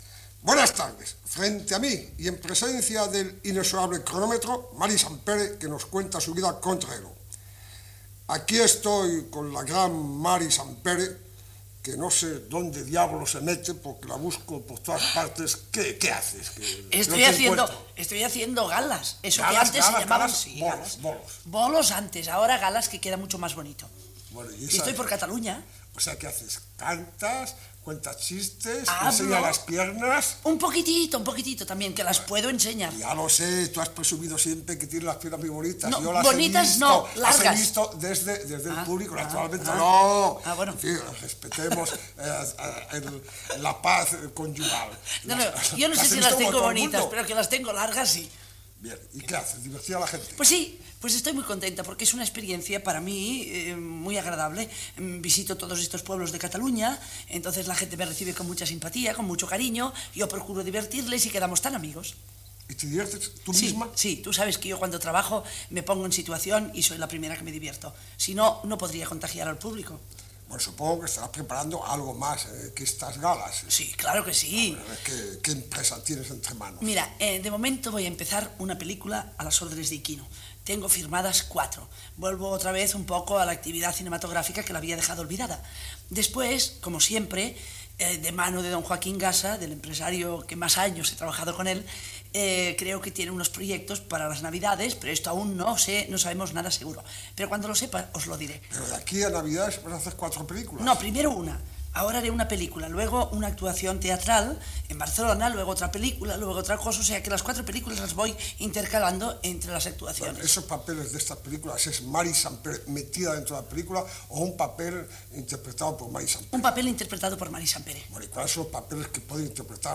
Presentació i entrevista a l'actriu Mary Santpere. Parla de la seva feina en aquell moment i de la pel·lícula que farà amb el director i productor Ignacio Iquino
Entreteniment